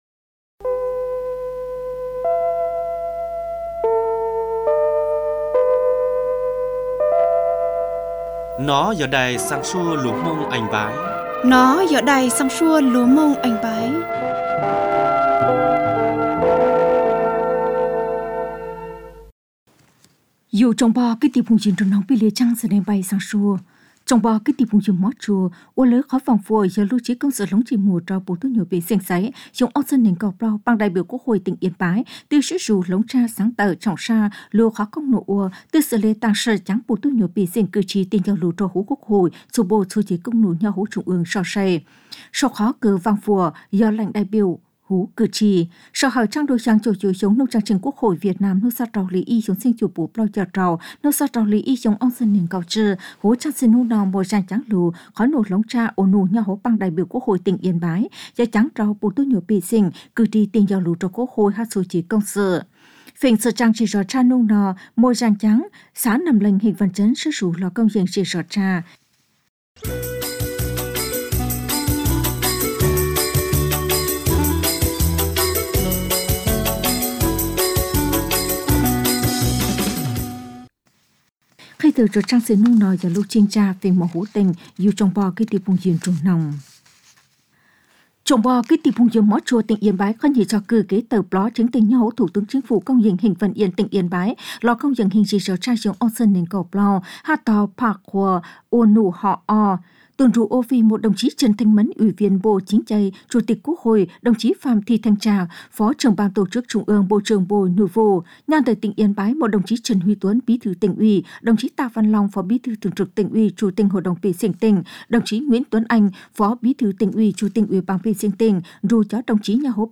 Chương trình phát thanh tiếng Mông ngày 4 1 2025